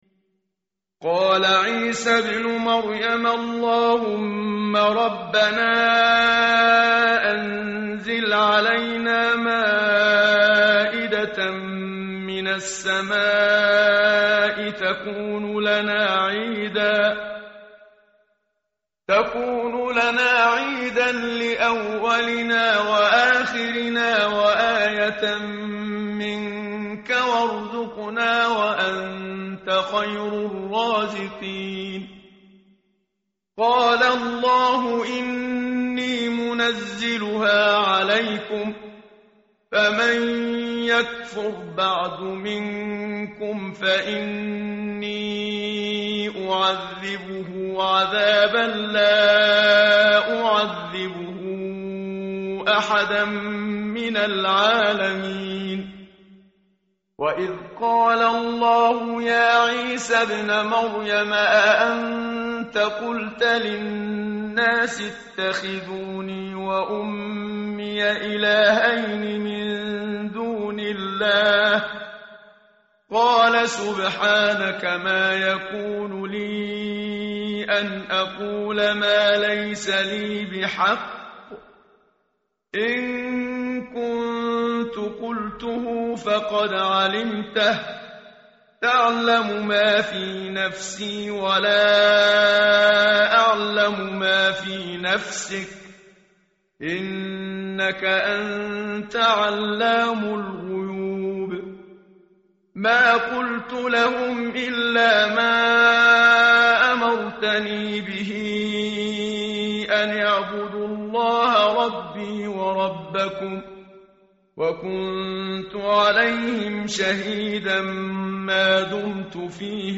tartil_menshavi_page_127.mp3